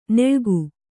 ♪ neḷgu